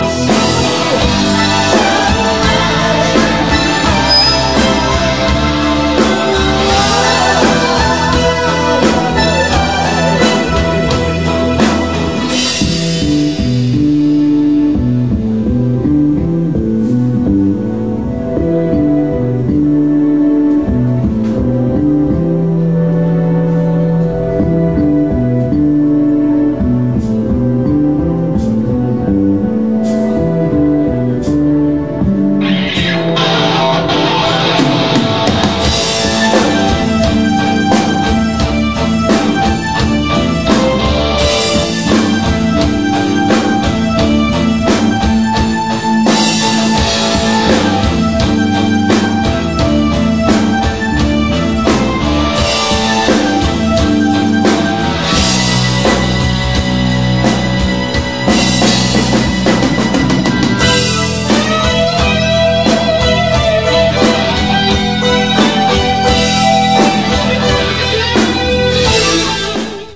例によってトラブルがあったり気合いが入りすぎて走り気味だったりしたものの、久しぶりにしては決めも比較的カチッと決まり、持ち前の歌謡ロックが良かったのか、観客のオバサンから「すごく良かった」と握手される一幕もありました。
vocal
guitar
keybords,chorus
bass,chorus
drums